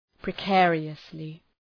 Προφορά
{prı’keərıəslı}